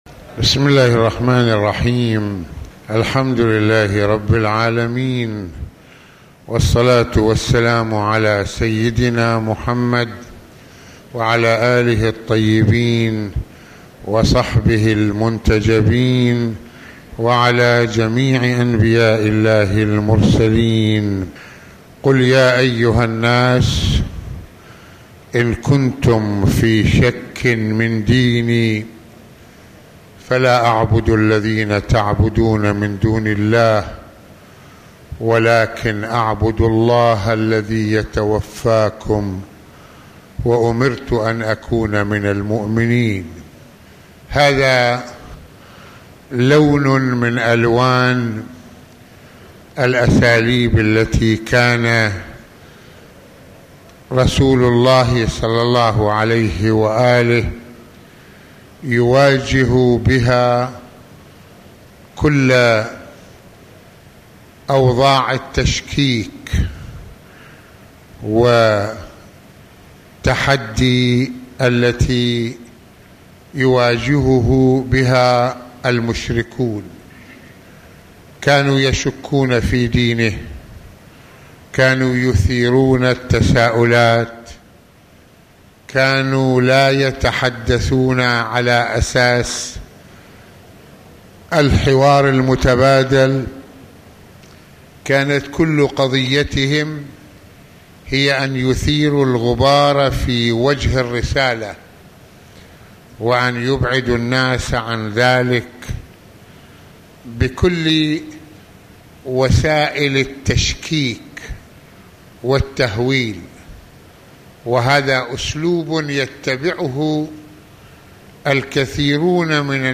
- المناسبة : موعظة ليلة الجمعة المكان : مسجد الإمامين الحسنين (ع) المدة : 25د | 21ث المواضيع : تشكيك الكفار بدين الرسول (ص) - تحدي النبي (ص) للكافرين بإصراره على الحق - الانسان وممارسة الحرية - نتائج الهدى والضلال على الانسان.